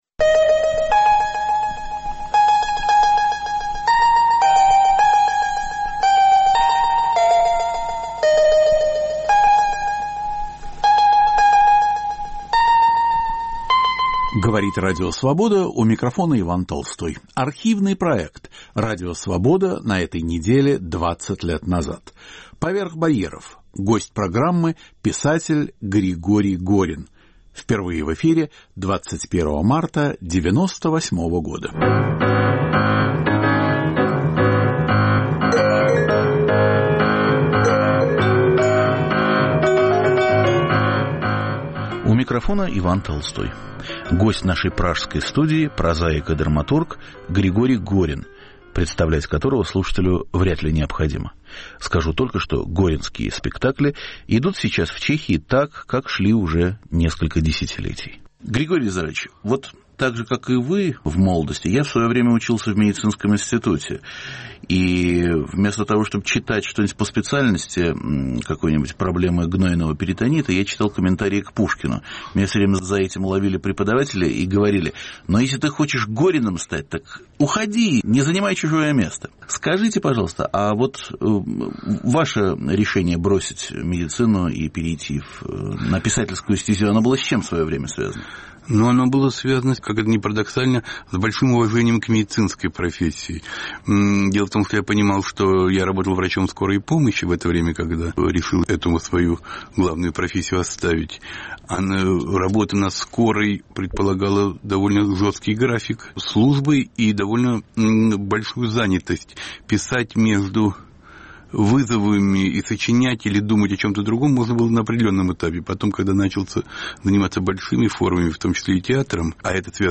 Радио Свобода на этой неделе 20 лет назад. В студии писатель Григорий Горин